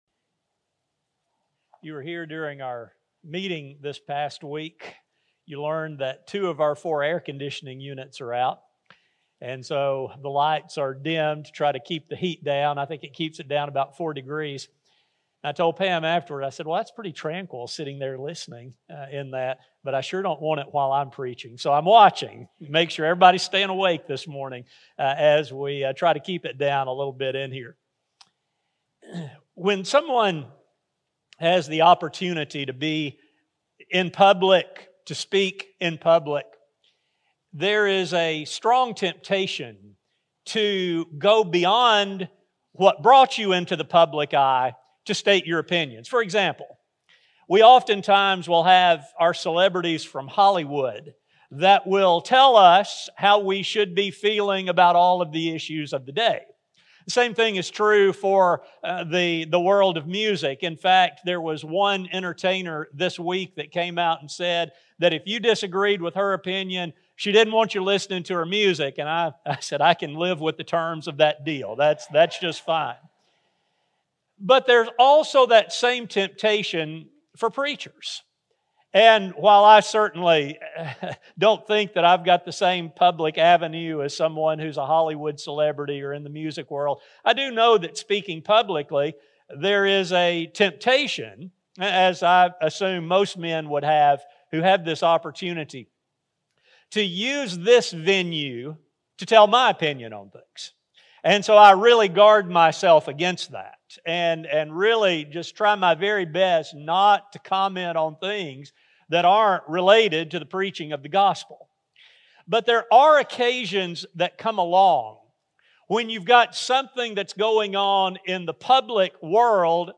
This study will not only briefly trace how we have reached this point in our debates, but also what actions Christians can take in loving their neighbors. A sermon recording